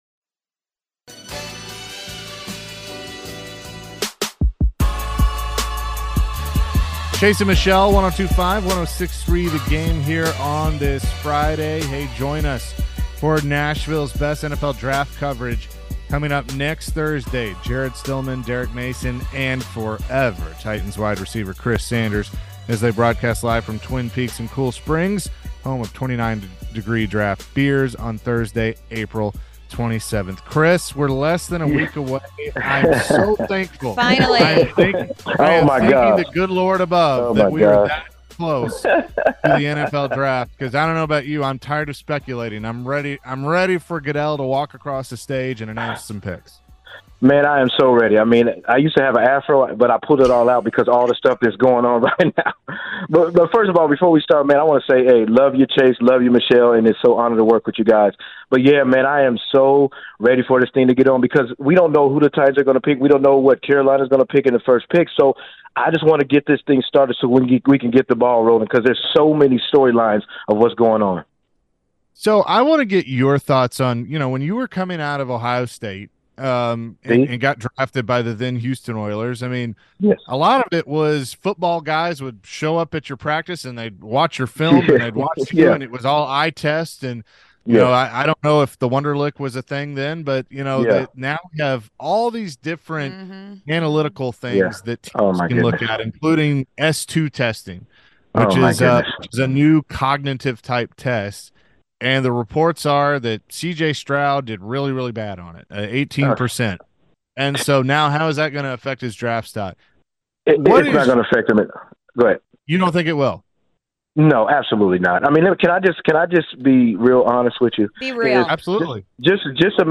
Chris Sanders Interview (4-21-23)